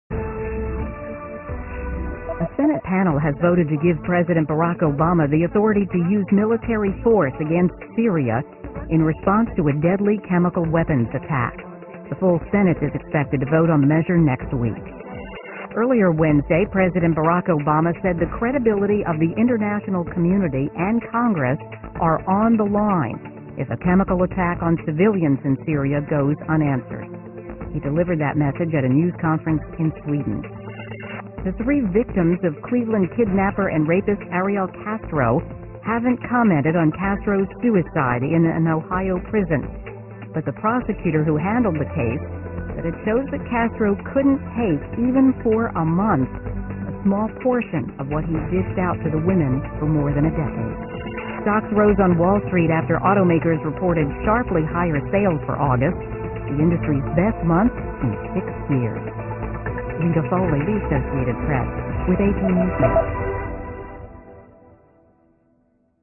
电视新闻片长一分钟，一般包括五个小段，简明扼要，语言规范，便于大家快速了解世界大事。